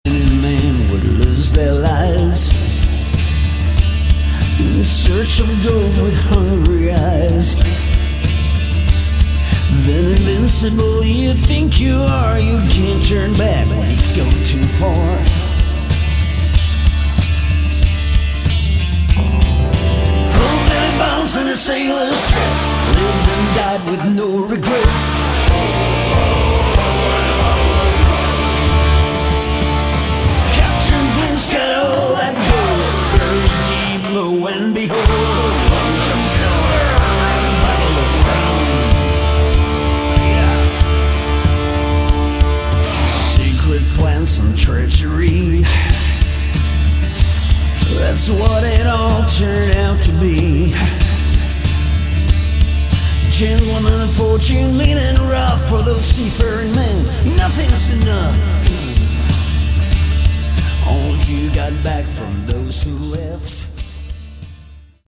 Category: Melodic Rock
guitar
drums
keyboards
bass
vocals, guitar
backing vocals